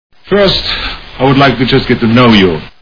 Kindergarten Cop Movie Sound Bites